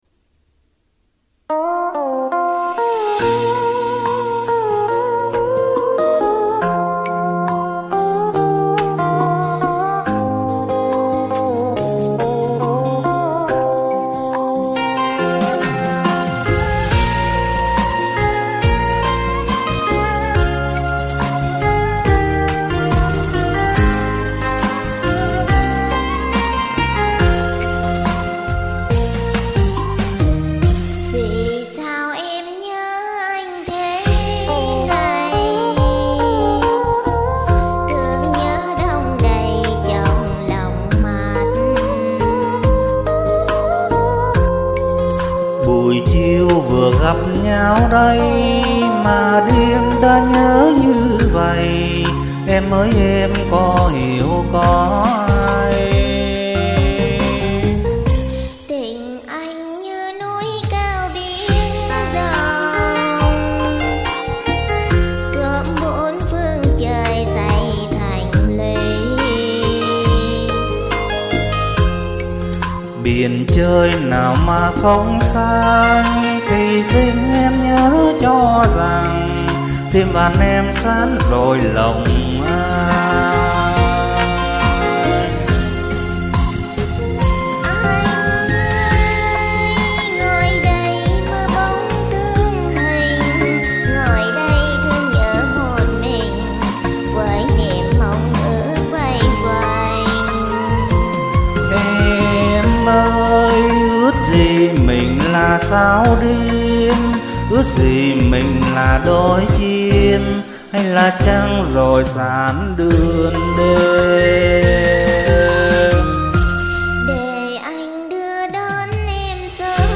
Tôi và bạn tôi đã song ca